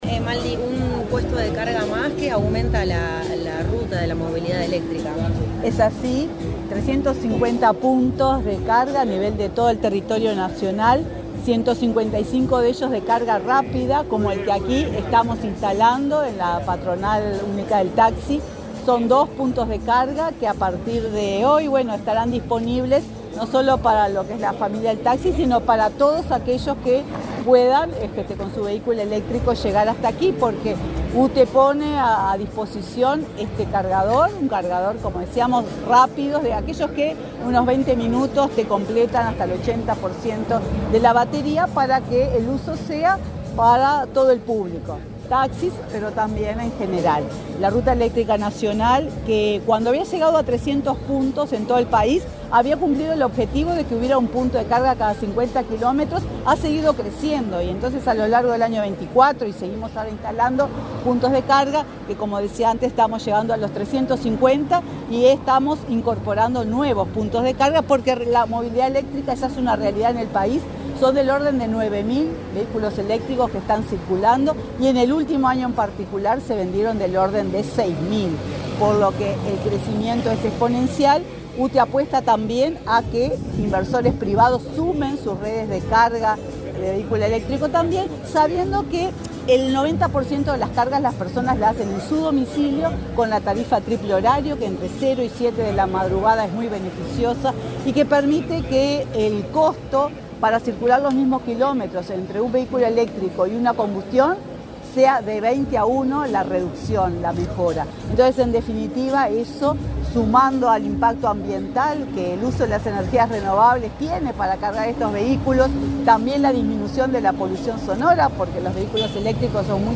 Declaraciones de la presidenta de UTE, Silvia Emaldi
Declaraciones de la presidenta de UTE, Silvia Emaldi 25/02/2025 Compartir Facebook X Copiar enlace WhatsApp LinkedIn La presidenta de la UTE, Silvia Emaldi, dialogó con la prensa, durante el acto de inauguración de cargadores eléctricos, instalados en la sede de la Gremial Única del Taxi.